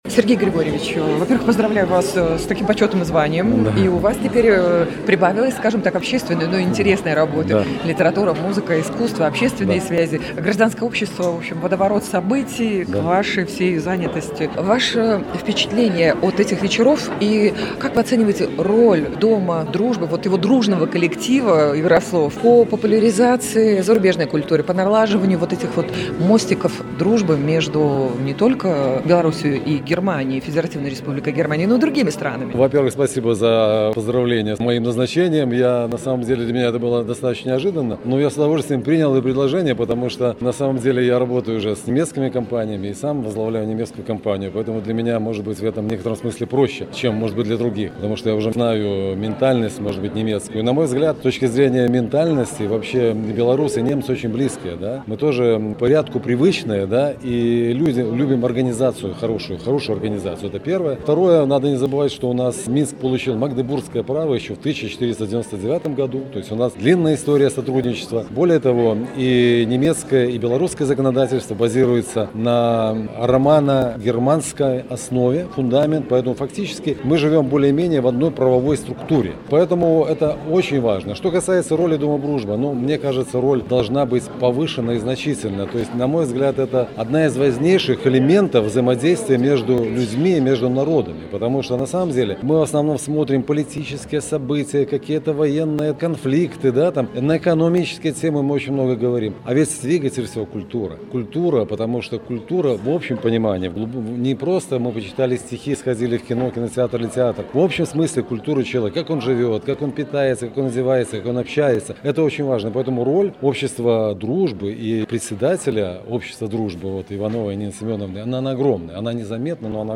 В Доме дружбы прошла 2 часть литературного вечера, посвящённого творчеству Германа Гессе